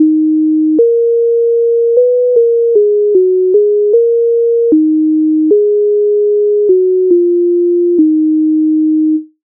MIDI файл завантажено в тональності es-moll